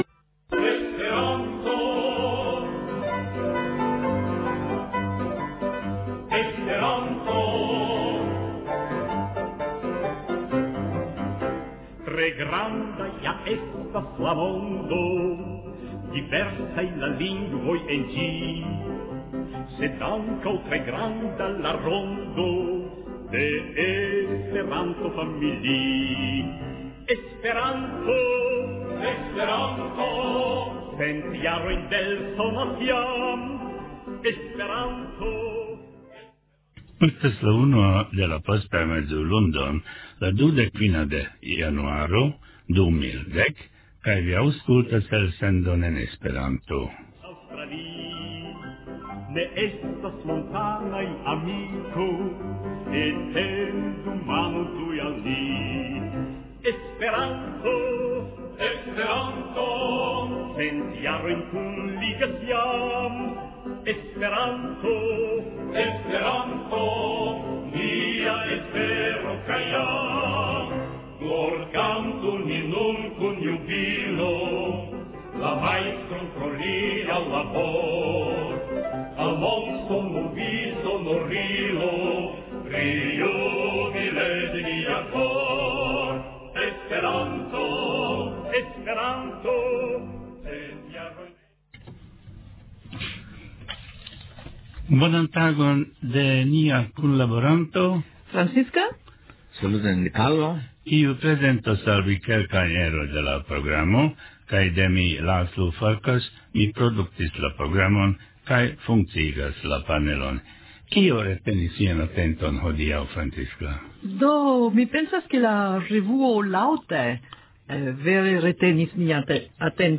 Legado